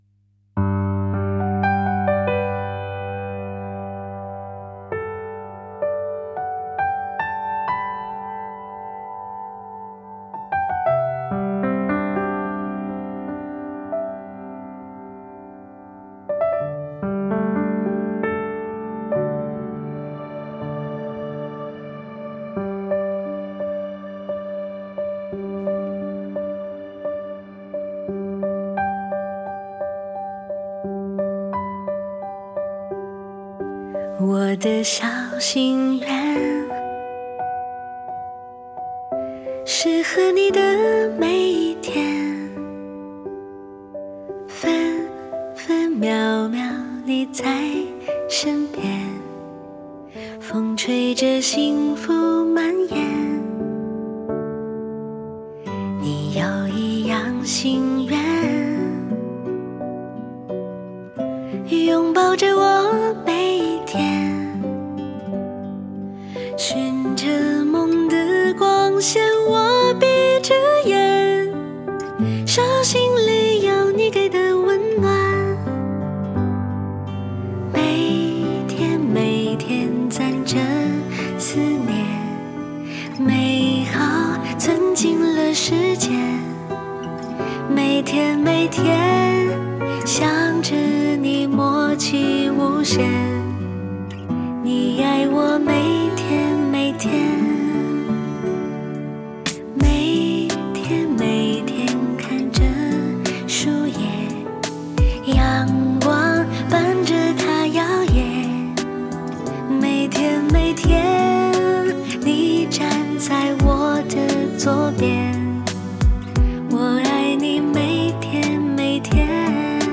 是一首充满情感的歌曲
Ps：在线试听为压缩音质节选，体验无损音质请下载完整版 https